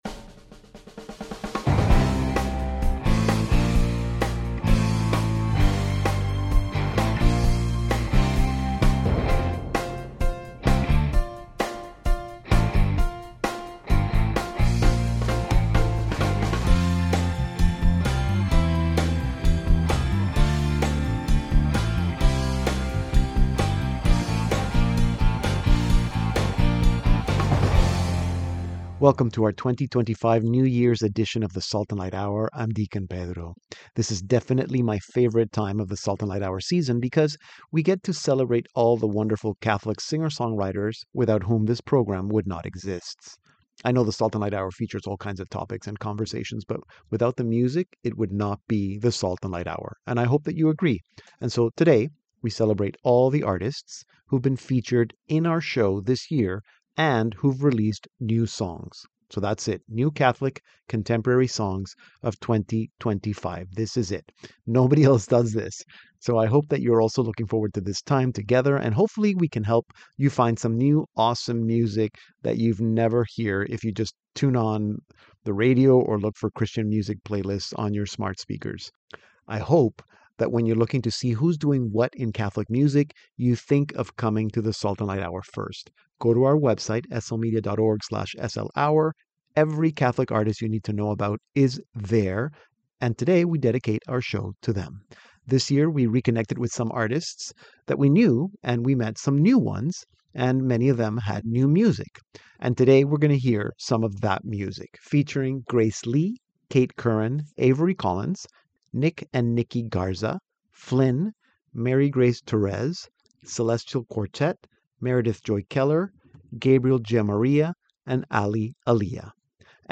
Inspiring messages, insightful interviews, interesting commentary and music; plus great segments from our contributors. Stay current with the Catholic Church in Canada and the world, and nourish your faith with the SLHour.